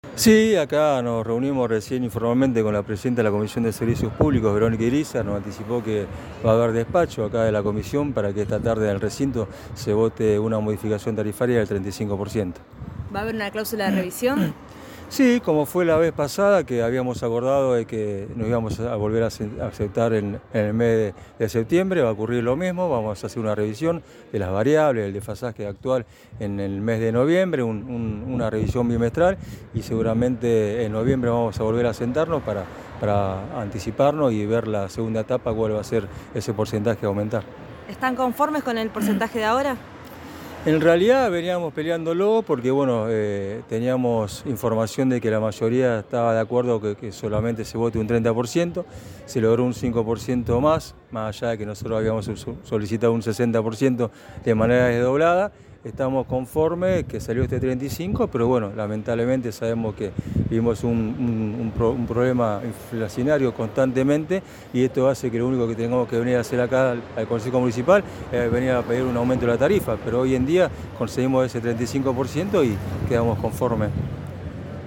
en diálogo con el móvil de Cadena 3 Rosario, en Siempre Juntos.